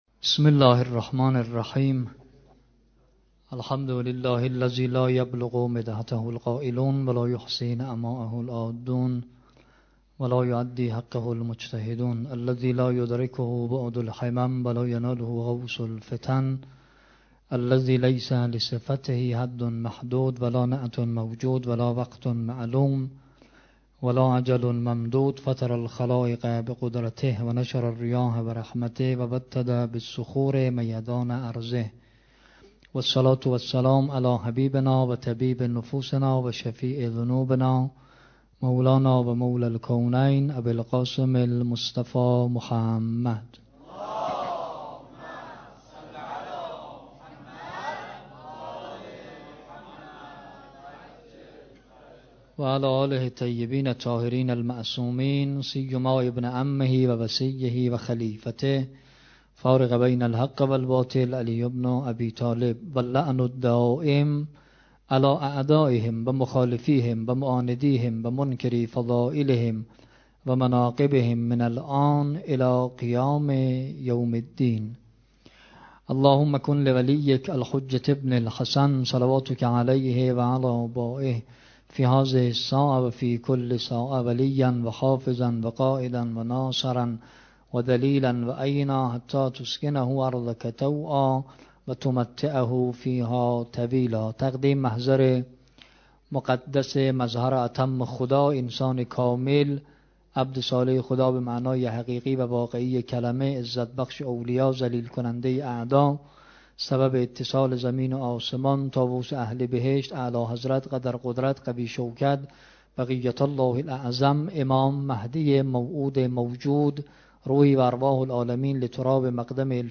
جلسه هفتگی
سخنرانی
روضه وداع حضرت سیدالشهدا(ع)